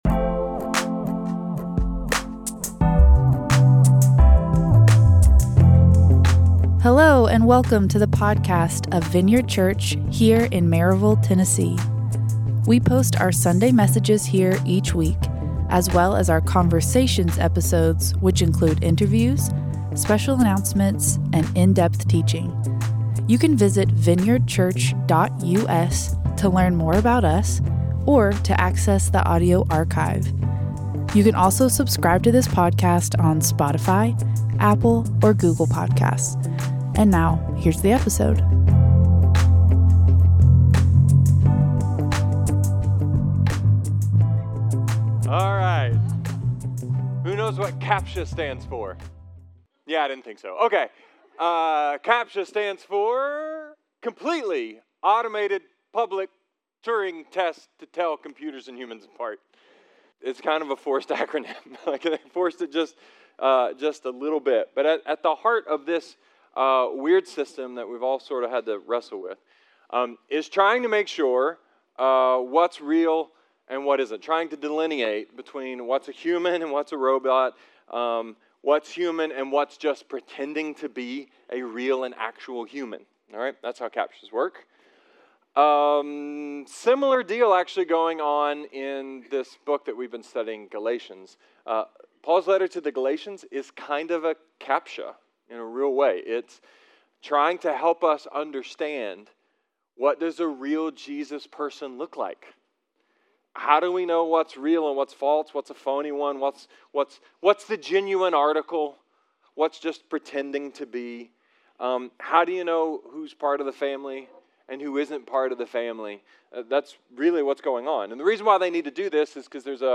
A sermon about robots, Torah, Frankenstein’s monster, and full devotion.